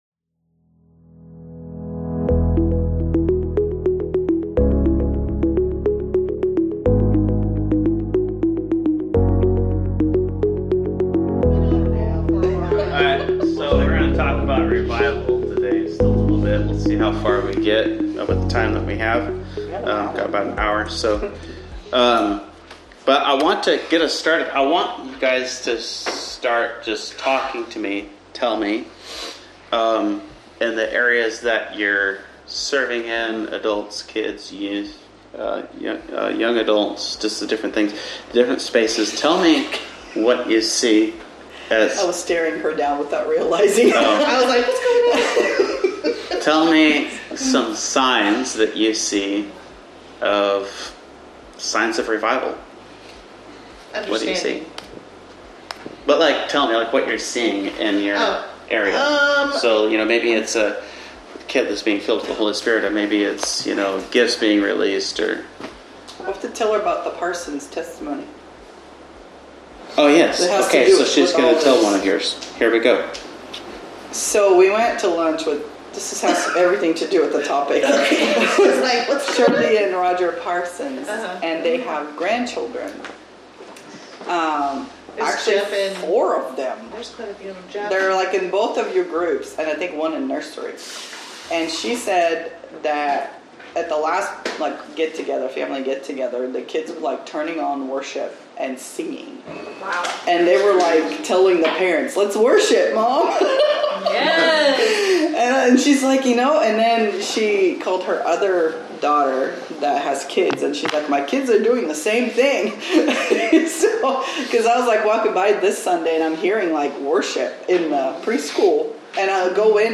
This episode is full of laughter, truth, and prophetic challenge.